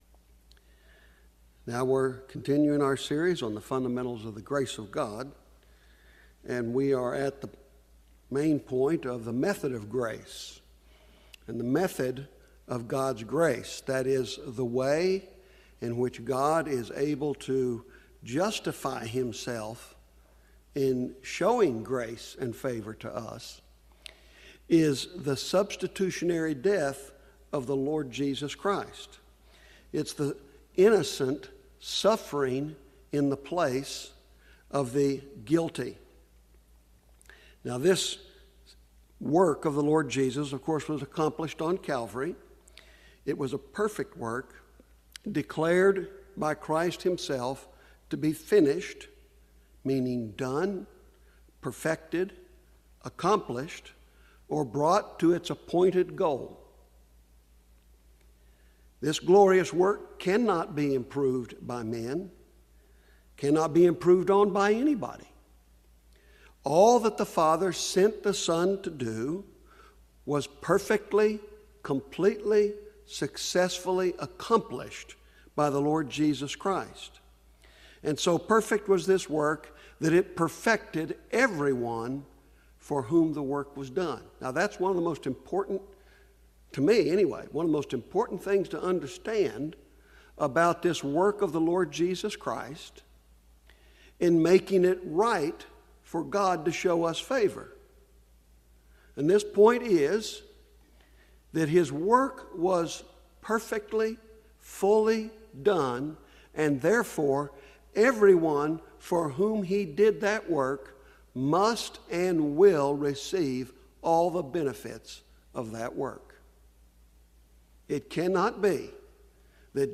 Fundamentals of Grace - 9 | SermonAudio Broadcaster is Live View the Live Stream Share this sermon Disabled by adblocker Copy URL Copied!